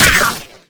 sentry_shoot_mini.wav